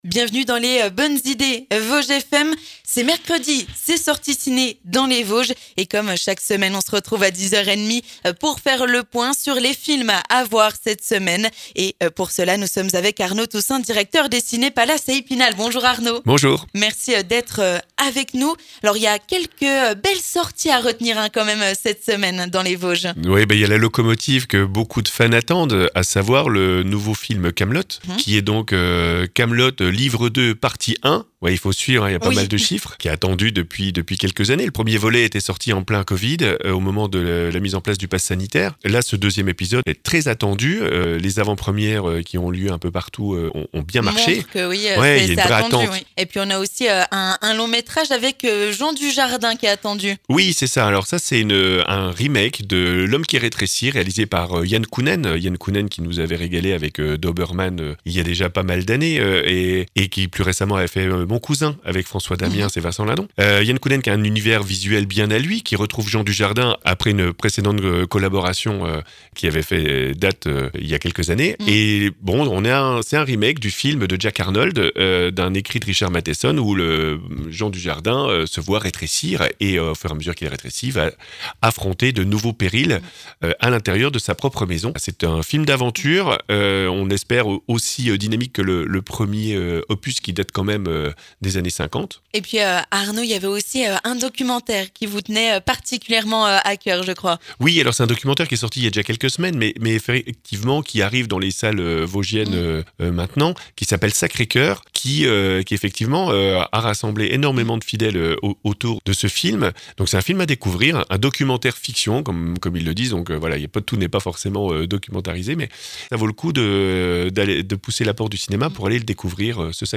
C'est mercredi, c'est sortie ciné dans les Bonnes Idées Vosges FM !